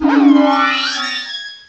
pokeemerald / sound / direct_sound_samples / cries / togekiss.aif
togekiss.aif